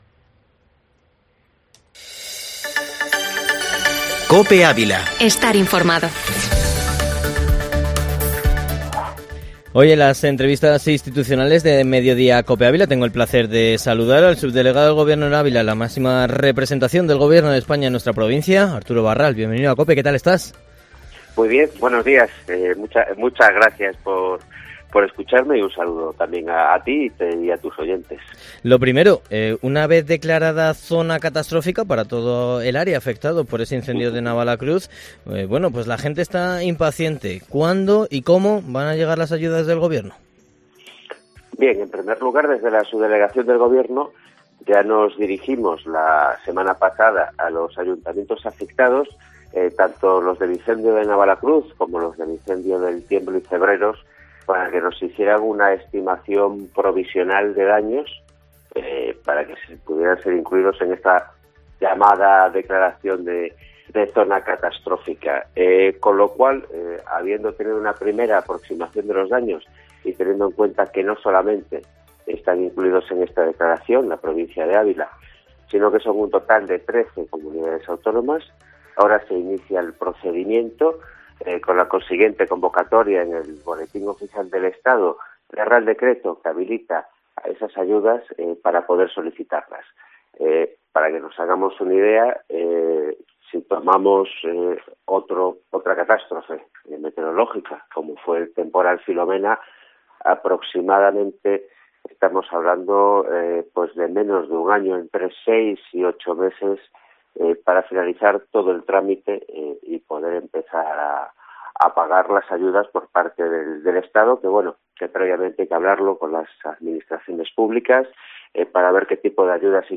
ENTREVISTA
Entrevista con el subdelegado del Gobierno en Ávila, Arturo Barral, en Mediodia COPE Ávila / 30-agosto